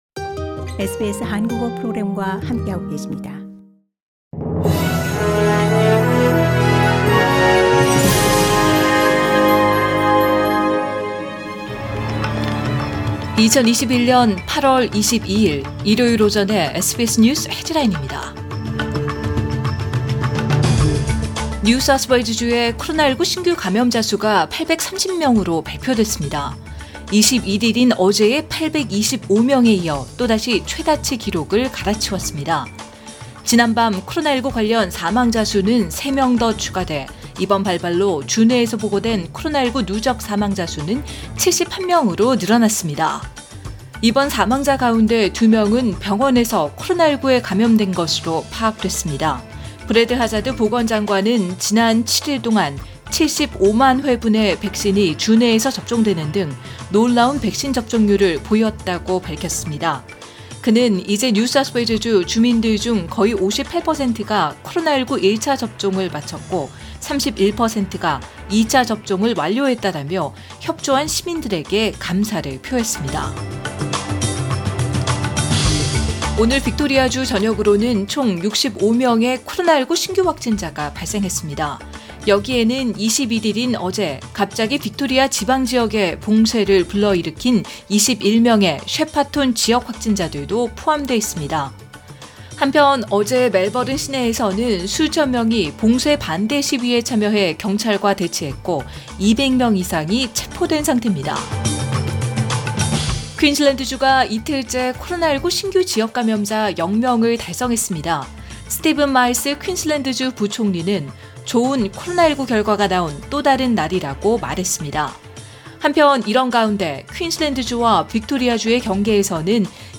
“SBS News Headlines” 2021년 8월 22일 주요 뉴스
2021년 8월 22일 일요일 오전의 SBS 뉴스 헤드라인입니다.